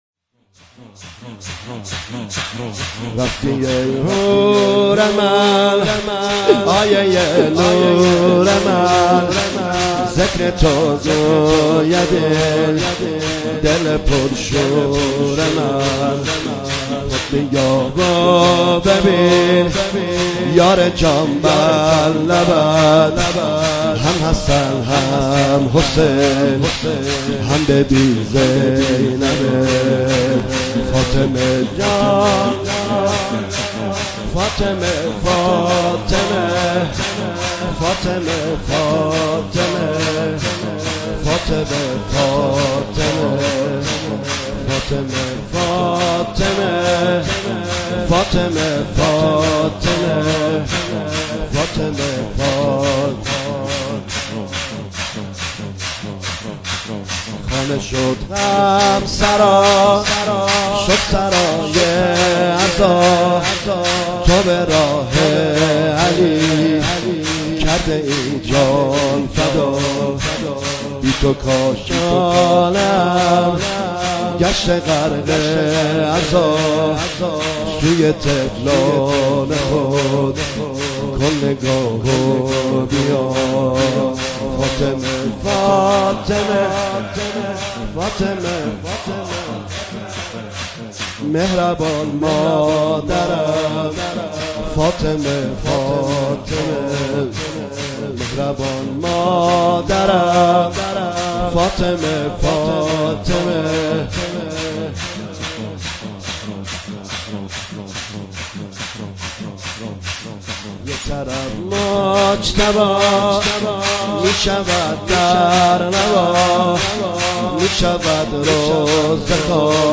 مداحی جدید
مراسم هفتگی 26 بهمن 1397